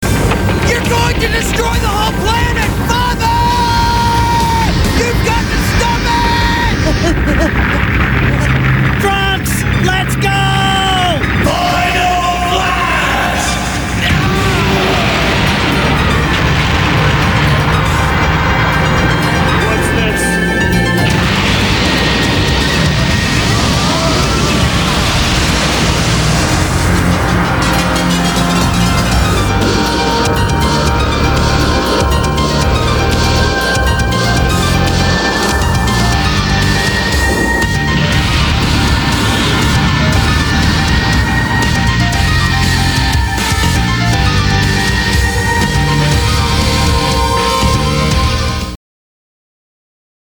DBZ Quotes Recorded and Music